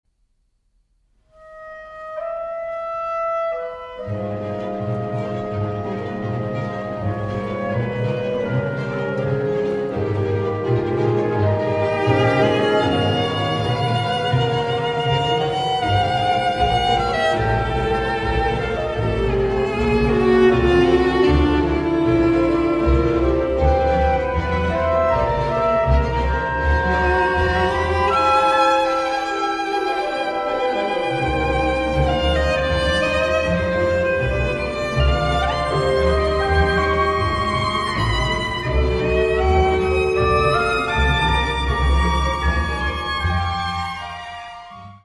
Tempo commodo – (4:04)